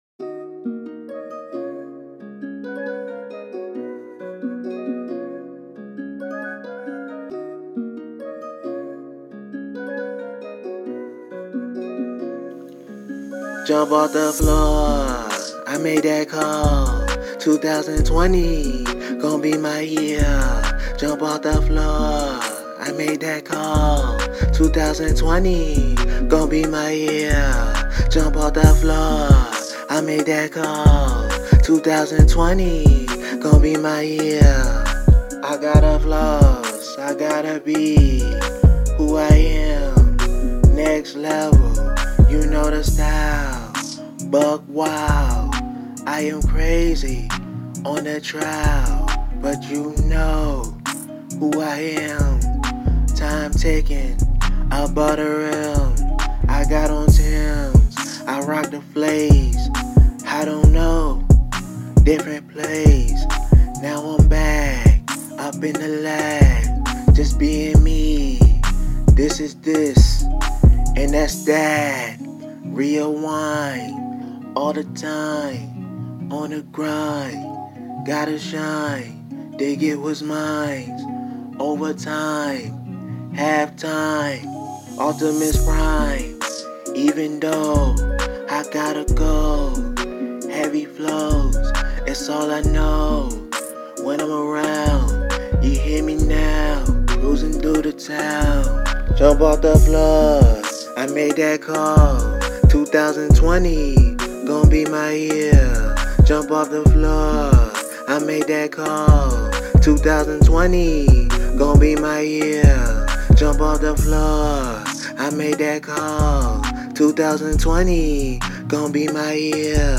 Hiphop
it's a club party and radio song